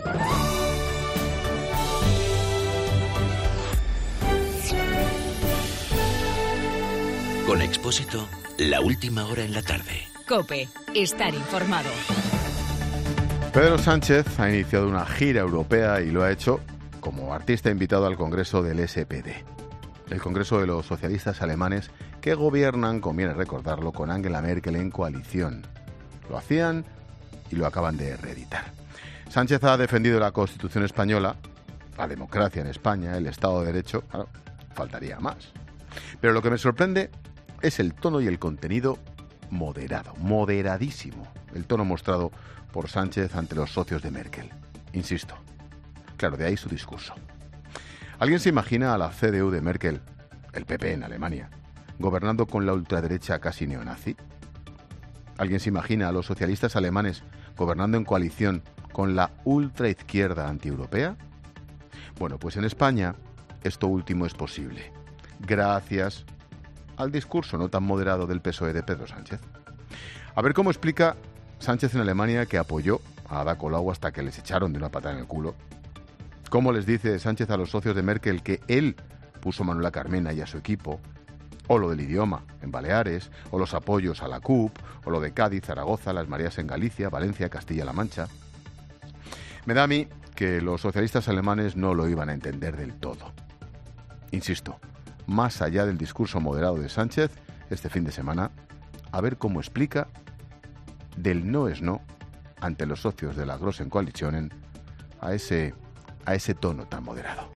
Monólogo de Expósito
El comentario de Ángel Expósito sobre la intervención de Pedro Sánchez en Alemania en el Congreso del SPD.